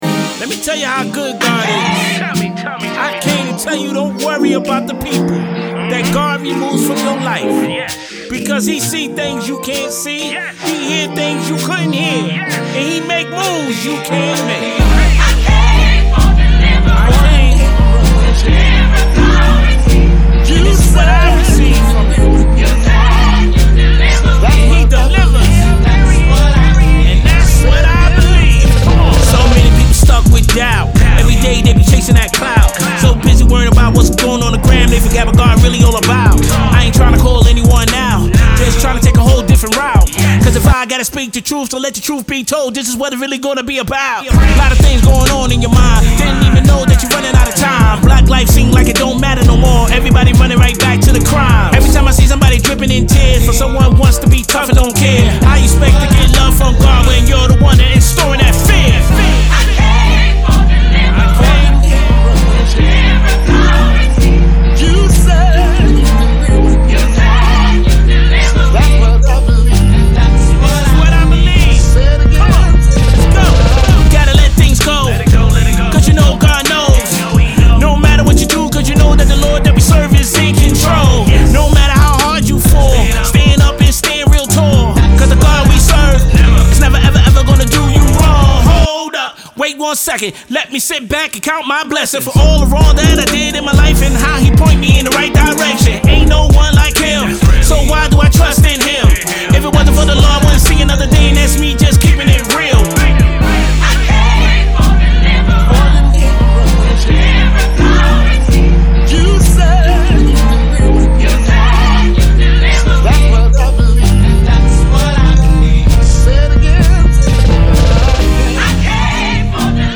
🙌 A powerful anthem of faith, freedom, and triumph.